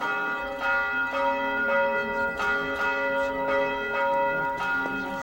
• BELLS CHIME STEREO AND DISTANT.wav
BELLS_CHIME_STEREO_AND_DISTANT_2NP.wav